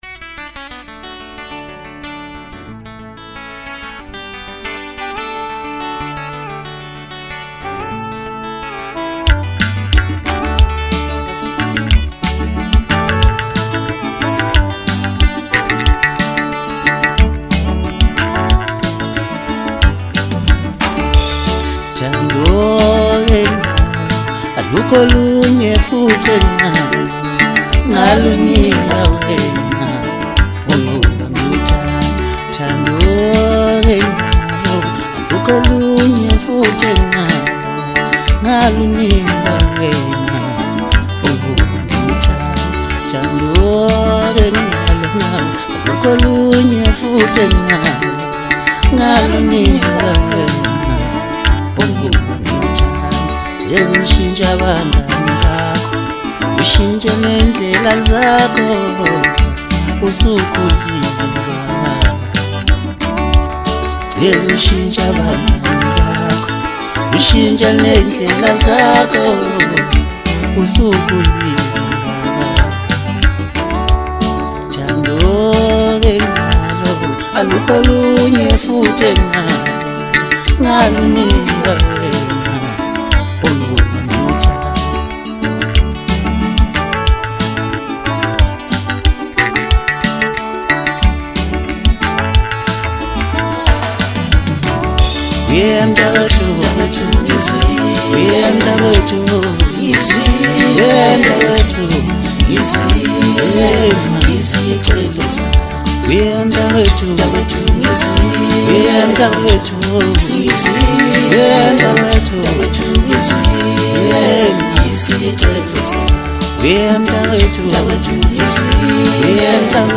emotionally charged
With its infectious beat and captivating vocals